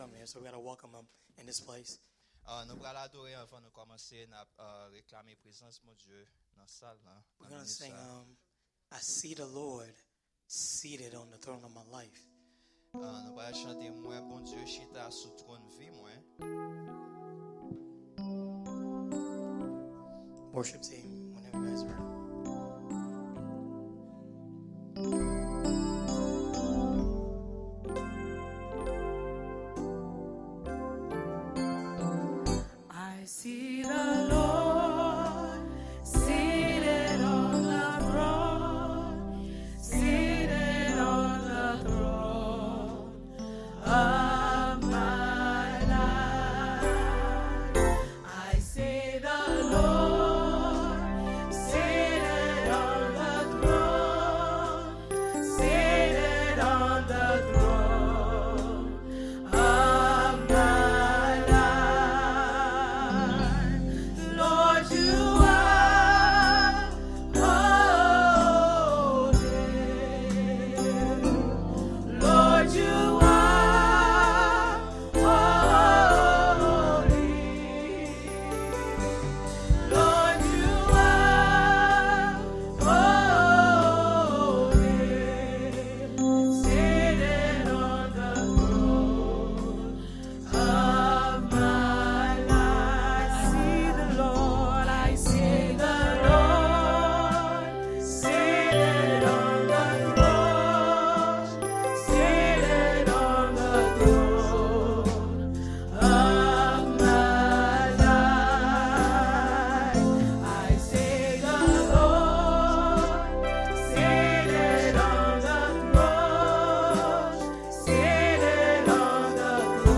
Power And Authority Through Christ – Welcome to Eben-Ezer C&MA Church